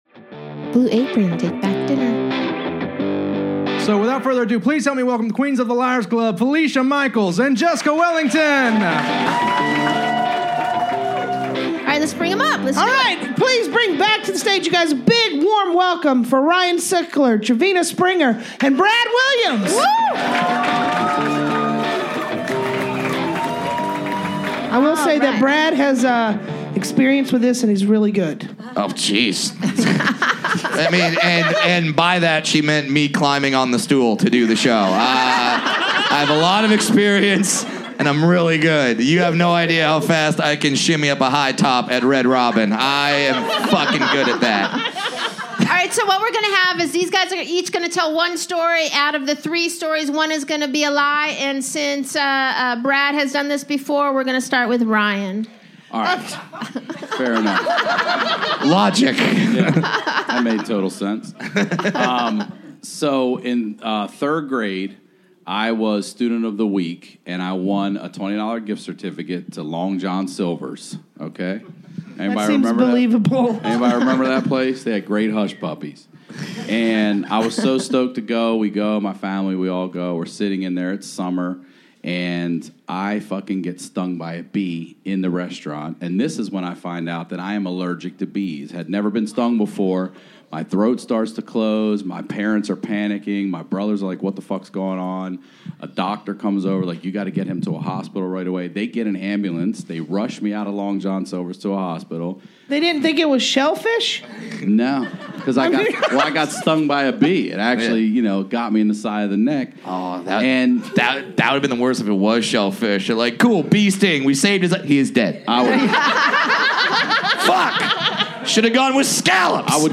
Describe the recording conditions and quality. LIVE from The Belly Room at The Comedy Store!